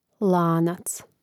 lánac lanac